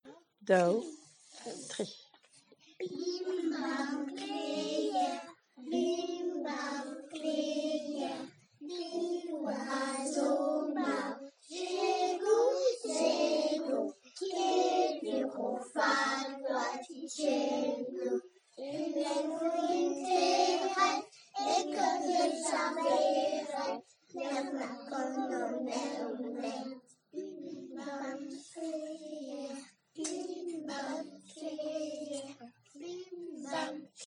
Bing, bang, kloc'hoù - Bretagne